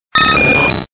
Fichier:Cri 0017 DP.ogg
contributions)Televersement cris 4G.